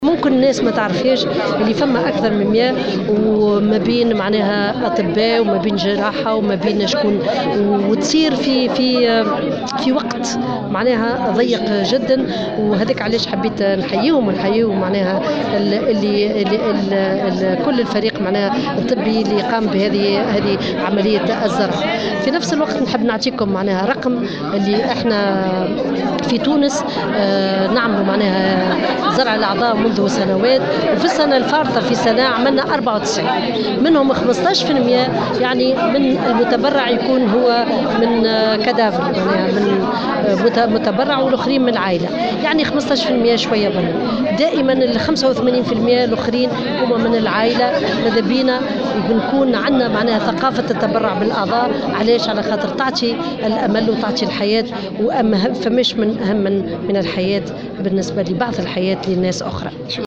وأضافت في تصريح لـ "الجوهرة أف أم" على هامش تنظيم عقد قران لزوجين مريضين بالقصور الكلوي في مستشفى شارل نيكول، أن زرع الأعضاء متأت بنسبة 85 بالمائة من عائلات المرضى في حين لم يتجاوز عدد المتطوعين بالتبرع بالأعضاء بعد وفاتهم، نسبة 15 بالمائة وفي نسق بطيء.